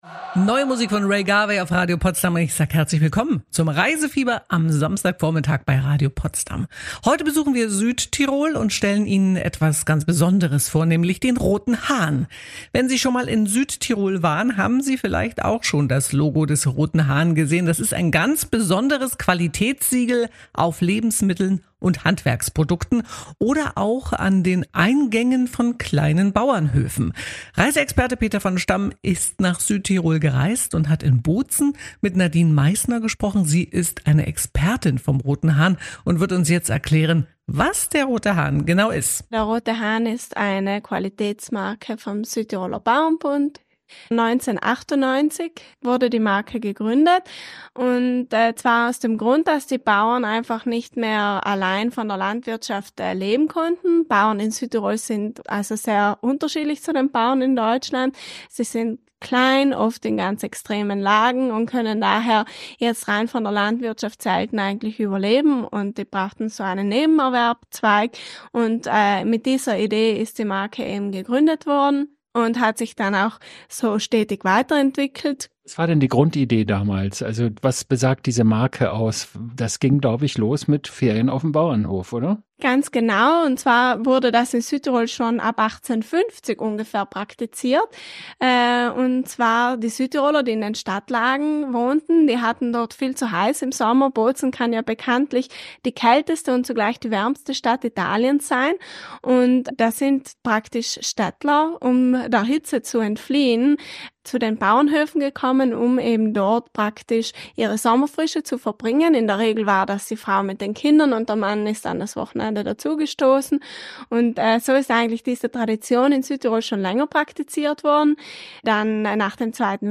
Dies ist der Mitschnitt der Sendung vom 09.11.2024. In diesem Reisefieber" besuchen wir Südtirol und stellen Ihnen etwas ganz Besonderes vor: Den „Roten Hahn“.